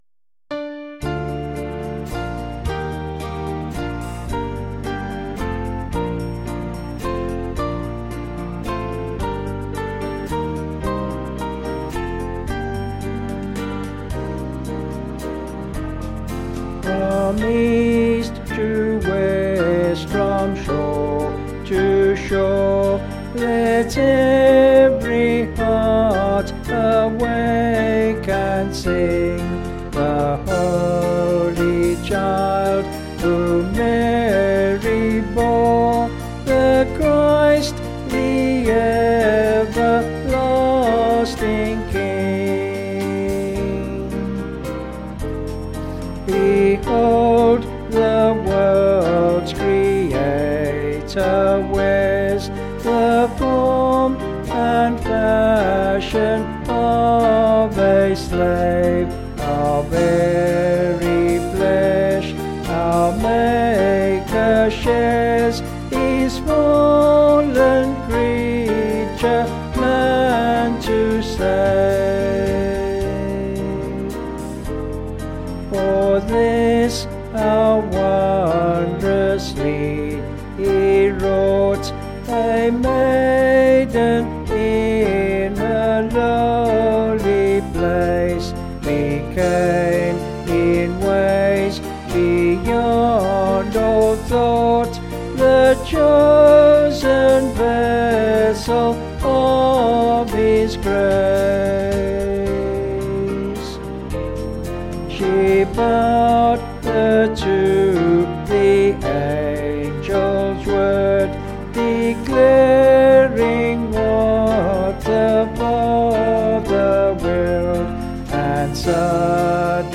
Vocals and Band   264.4kb Sung Lyrics